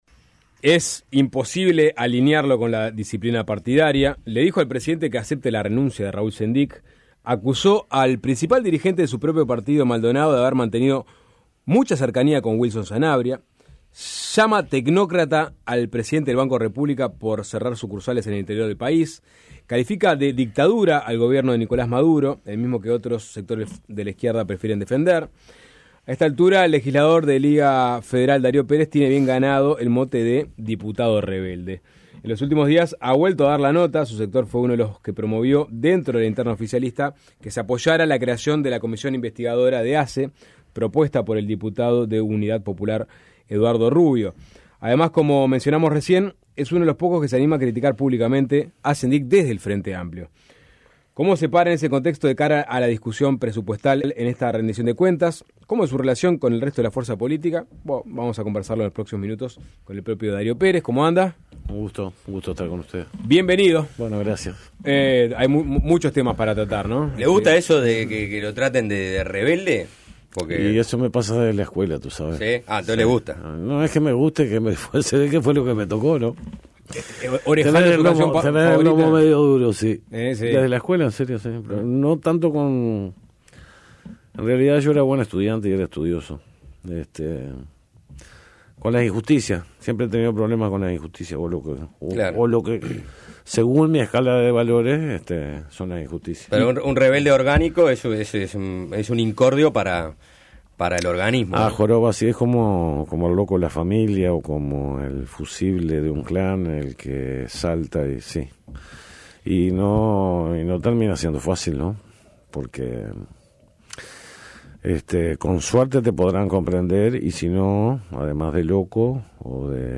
Entrevistado por Suena Tremendo, el legislador explicó que había negociado brindar el voto al proyecto de la marihuana con la condición de que se eduque sobre tema.